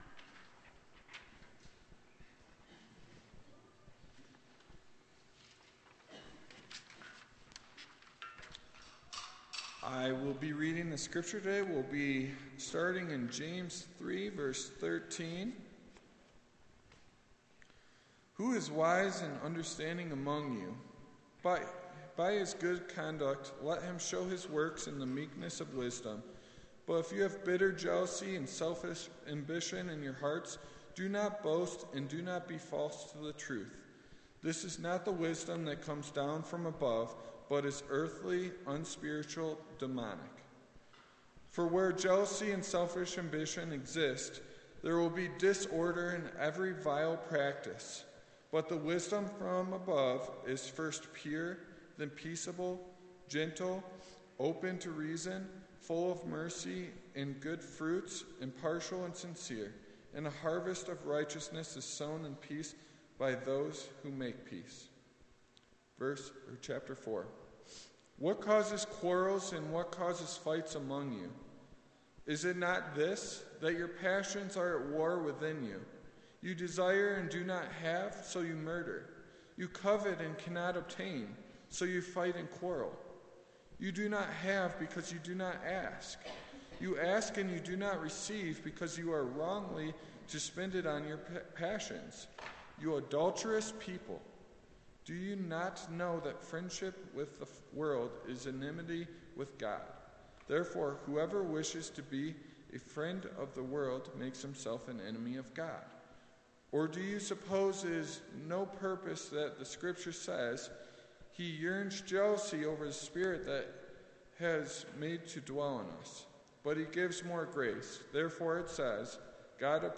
Sermon Archives | Aspen Ridge Church